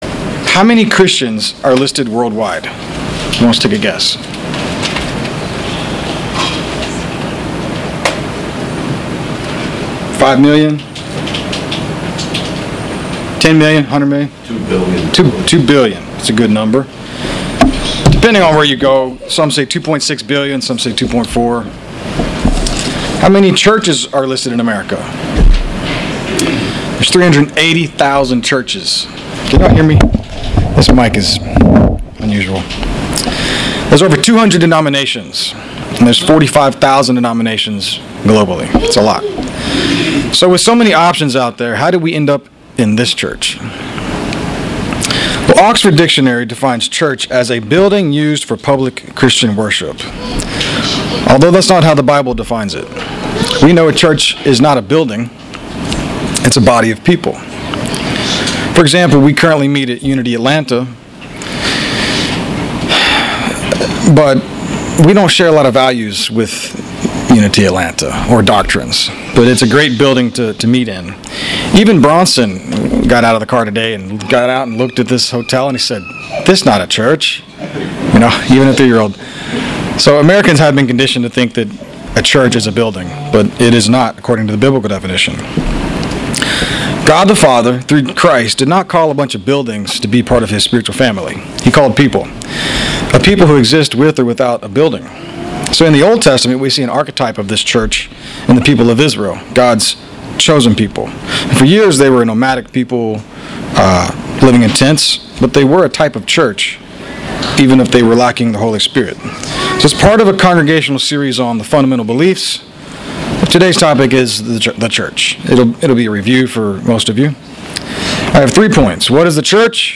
Given in Buford, GA